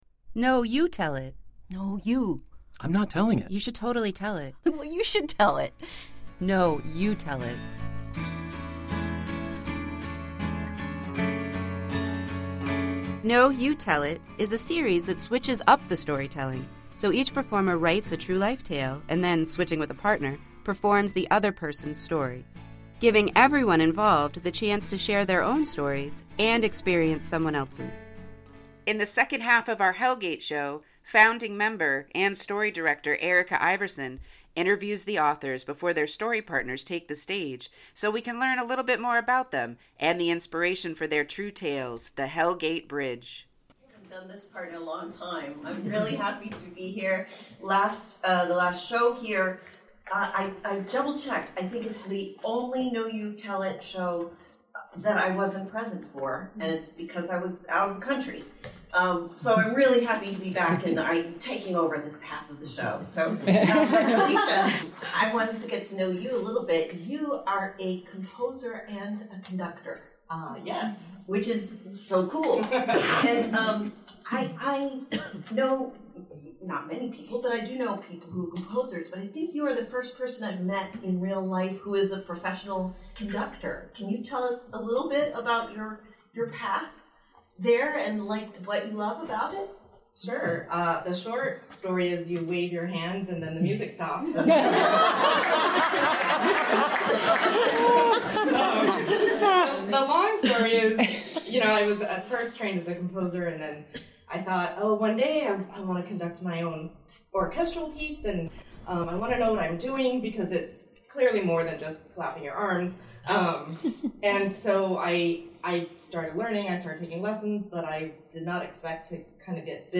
Four Queens storytellers traded true tales inspired by the history of the Hell Gate Bridge from the Greater Astoria Historical Society archives.